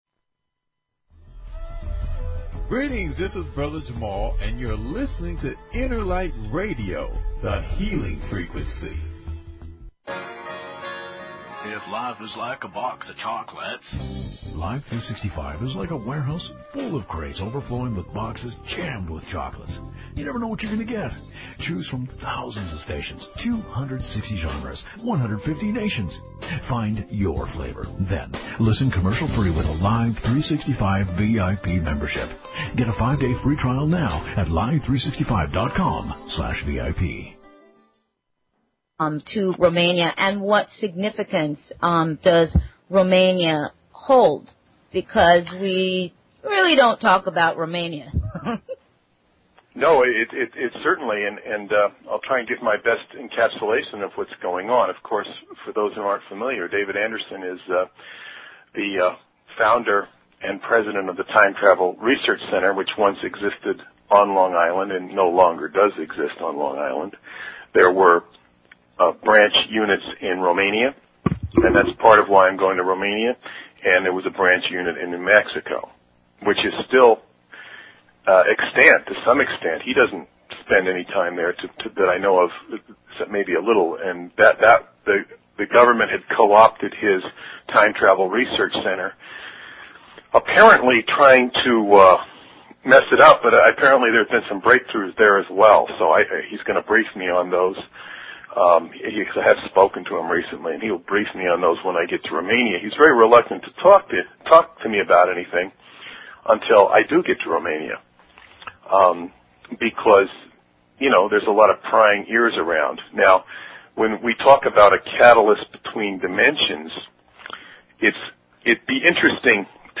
Talk Show Episode, Audio Podcast, Sovereign_Mind_Radio and Courtesy of BBS Radio on , show guests , about , categorized as